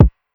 Almost Kick.wav